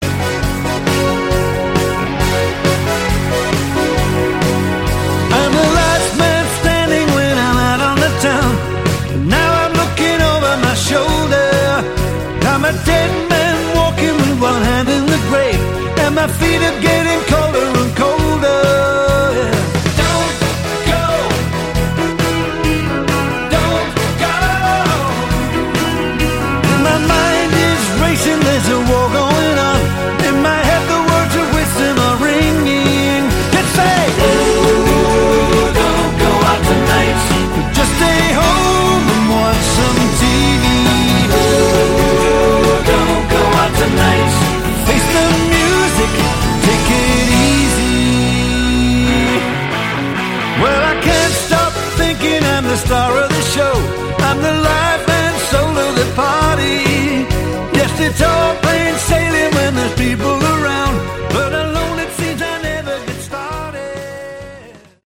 Category: Melodic Rock
vocals, bass
keyboards
guitar
cello
drums
guest harp
guest female voice